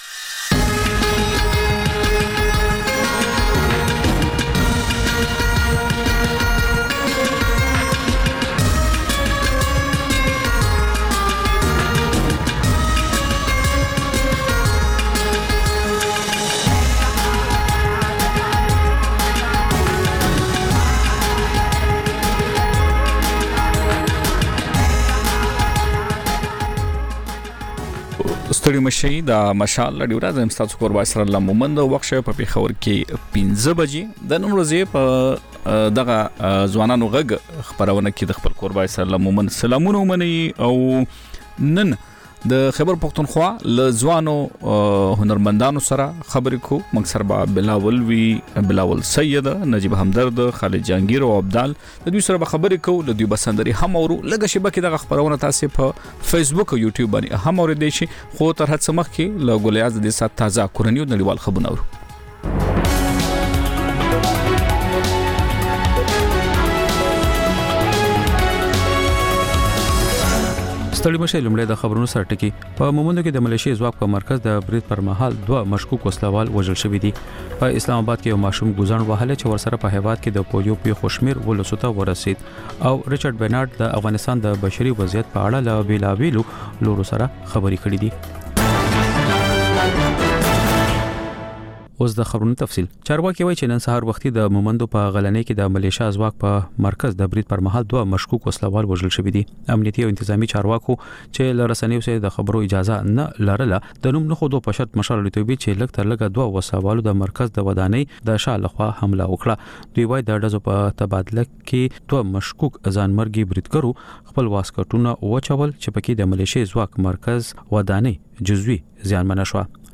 د مشال راډیو ماښامنۍ خپرونه. د خپرونې پیل له خبرونو کېږي، بیا ورپسې رپورټونه خپرېږي.
ځینې ورځې دا ماښامنۍ خپرونه مو یوې ژوندۍ اوونیزې خپرونې ته ځانګړې کړې وي چې تر خبرونو سمدستي وروسته خپرېږي.